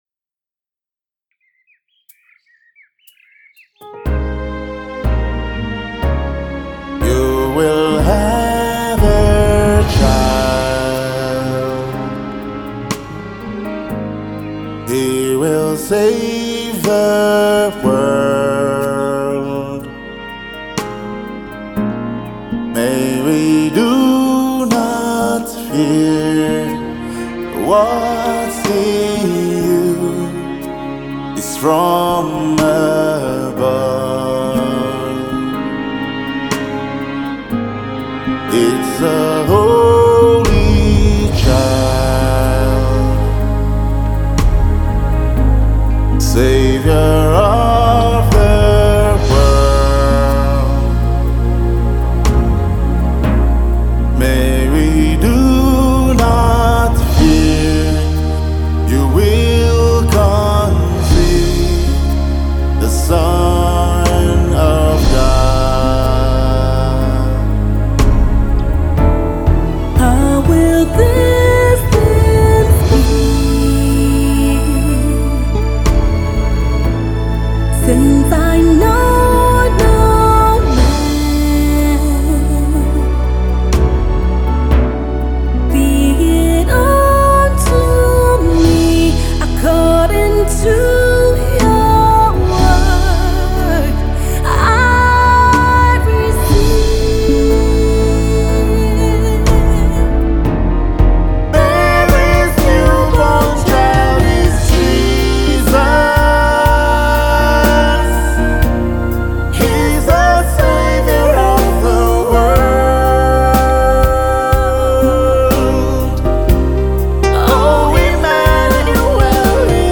Gospel music
lovely ballad duet
The duet comes just in time for the seasom of